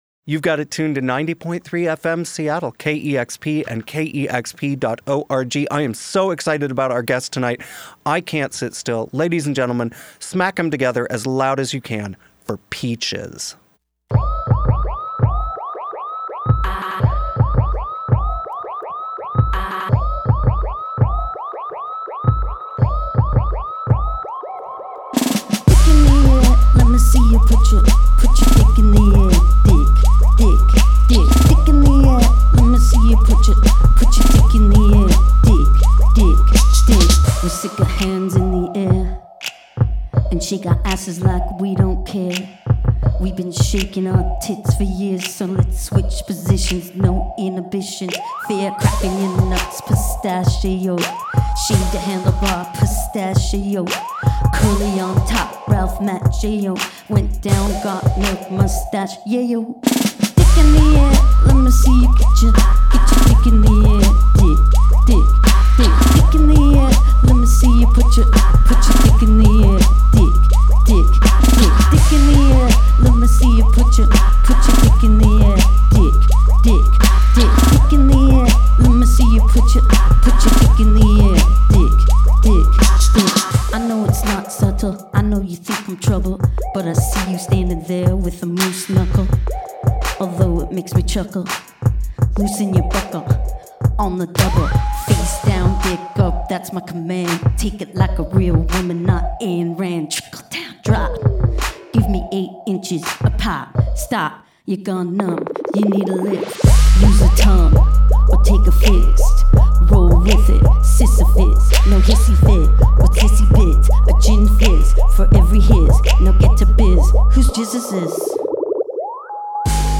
3 songs